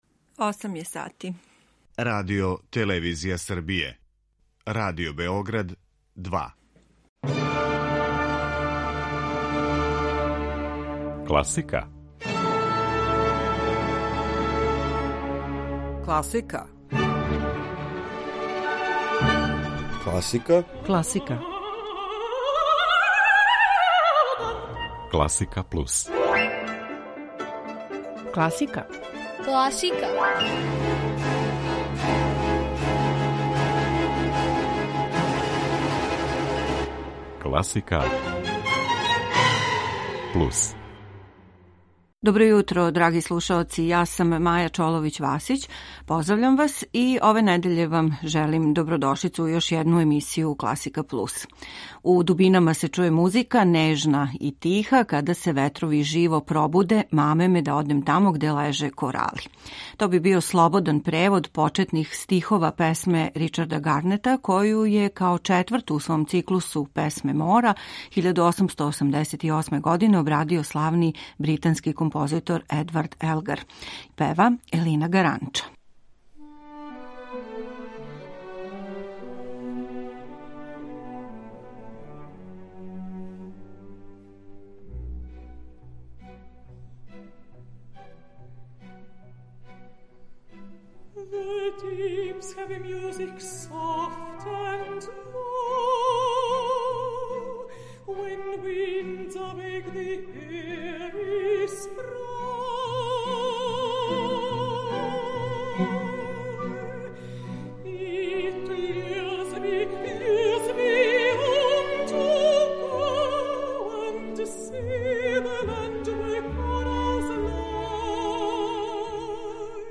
моторичност барокног покрета у концертима